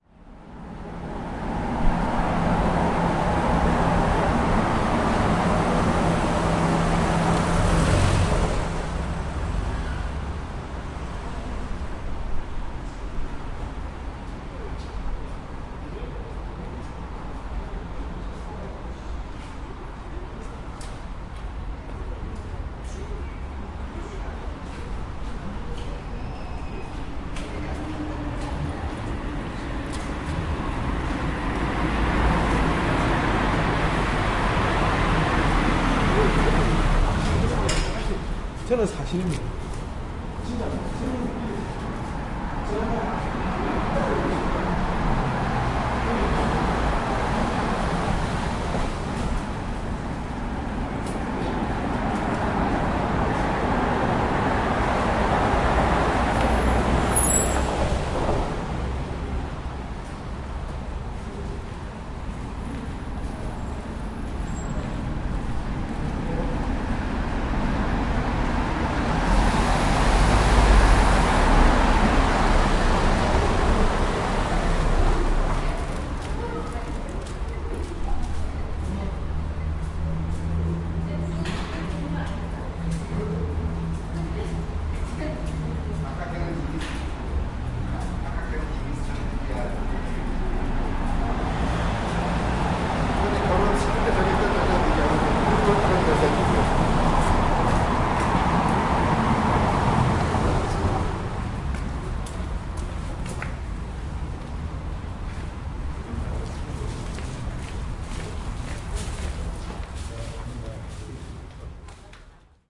描述：人们边走边用韩语交谈。
Tag: 场记录 脚步声 话音 韩语 韩国首尔